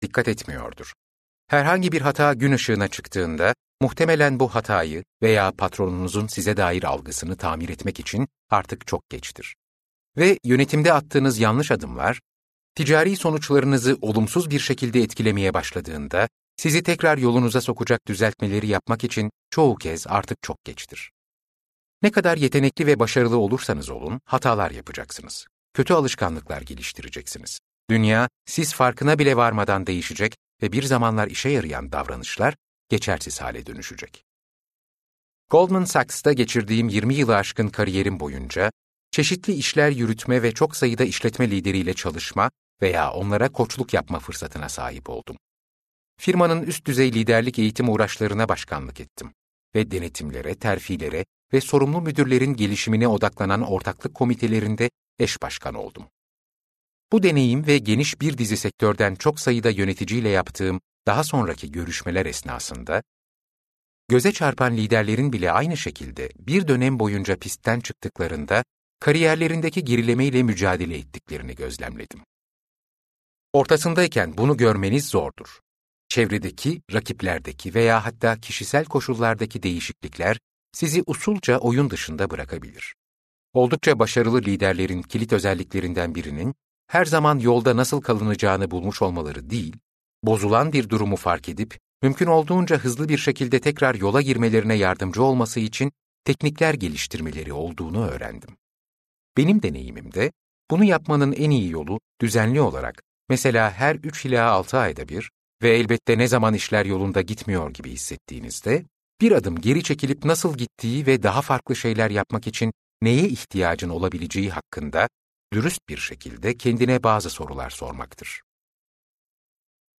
Aynadaki İnsana Sorulacak Sorular - Seslenen Kitap
Seslendiren